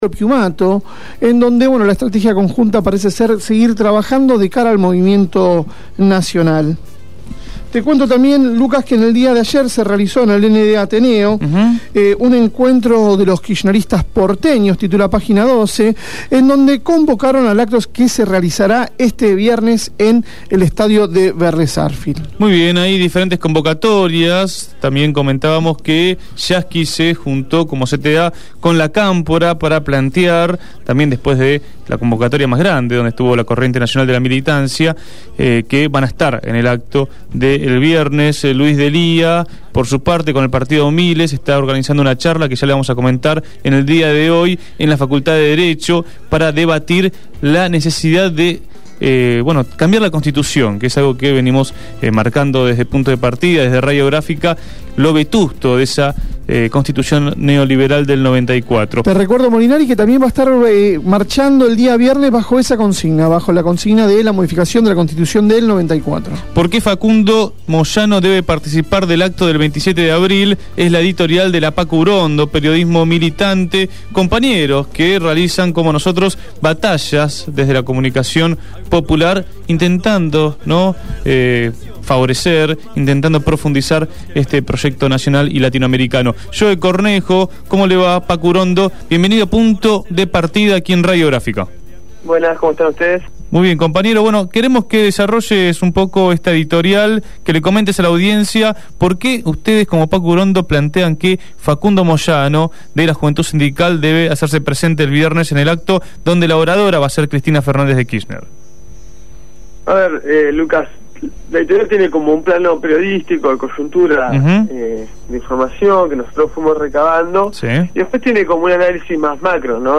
Escuchá la entrevista completa y los diferentes puntos de vista acerca de un tema de actualidad para el Campo Nacional.